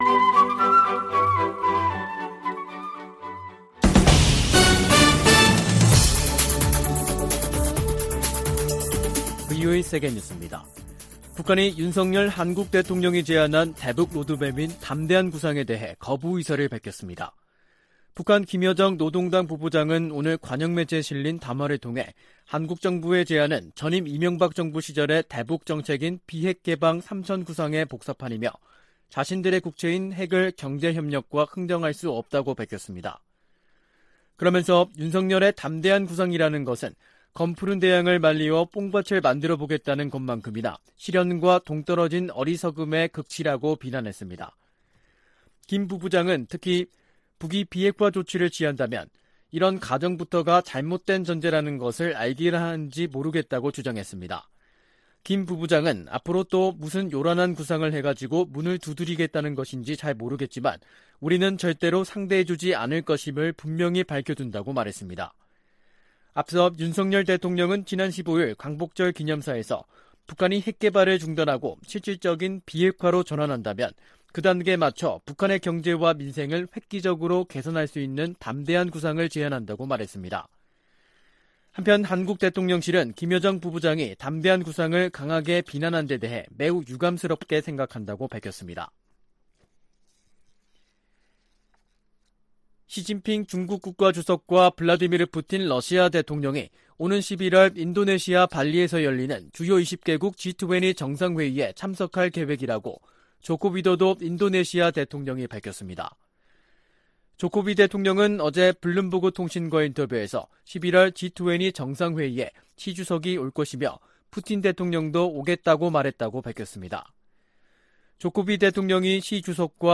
VOA 한국어 간판 뉴스 프로그램 '뉴스 투데이', 2022년 8월 19일 2부 방송입니다. 김여정 북한 노동당 부부장이 윤석열 한국 대통령의 '담대한 구상'을 정면 거부하는 담화를 냈습니다. 미 국무부는 북한이 대화 의지를 보이면 비핵화를 위한 점진적 단계가 시작되지만 이를 거부하고 있어 실질적 단계에 도입하지 못하고 있다고 밝혔습니다. 미국의 전문가들은 윤 한국 대통령의 한일 관계 개선 의지 표명을 긍정적으로 평가했습니다.